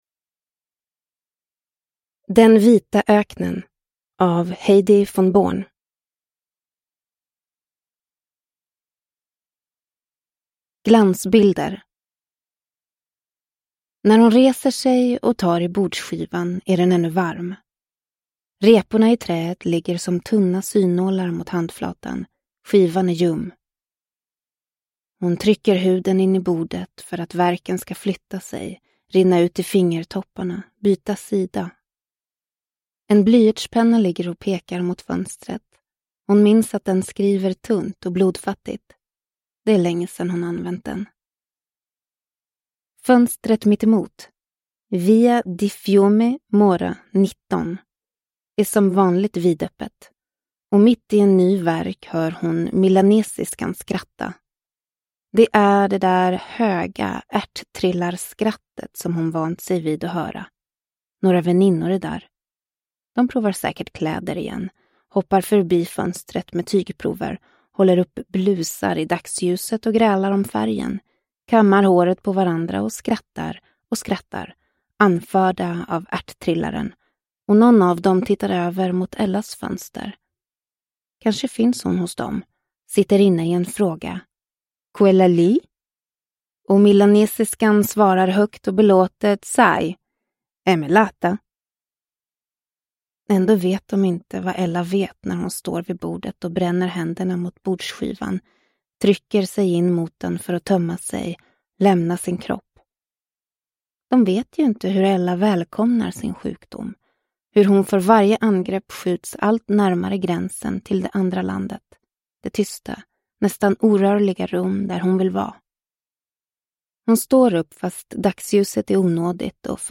Den vita öknen – Ljudbok – Laddas ner